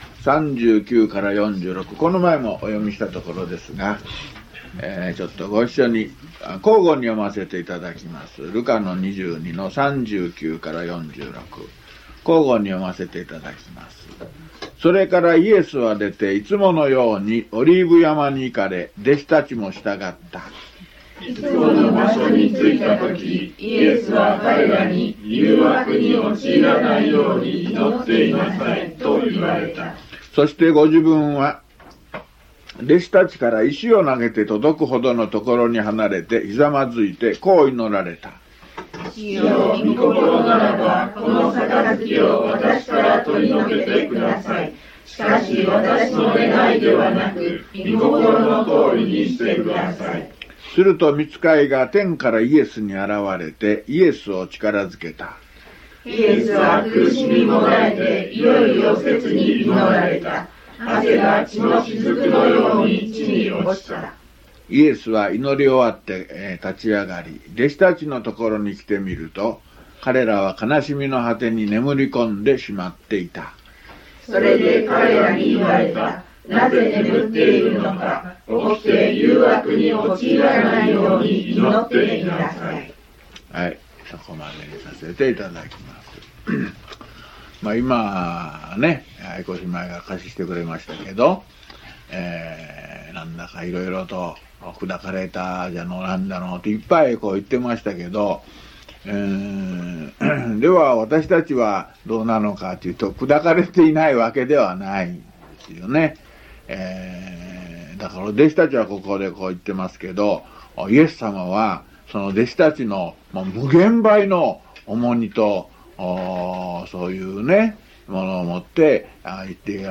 luke177mono.mp3